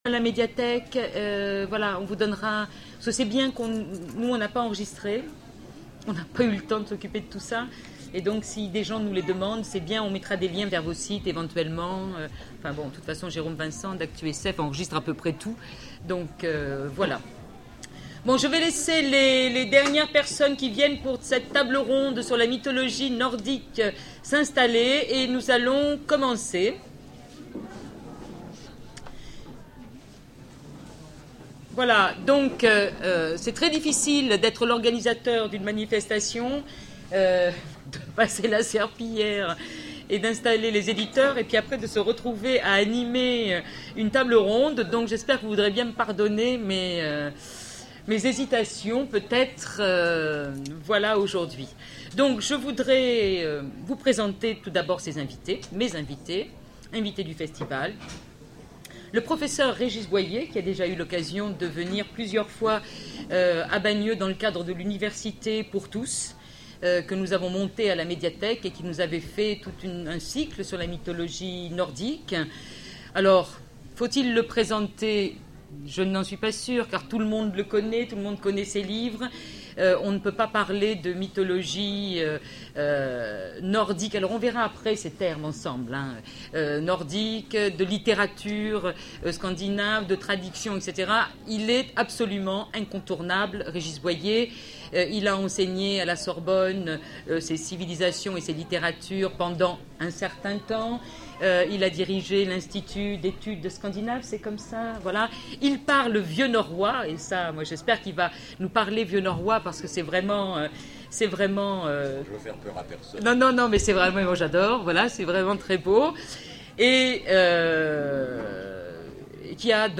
Voici l'enregistrement de la conférence La Mythologie Nordique lors du festival Zone Franche de Bagneux en février 2010